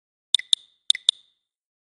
Message 4.aac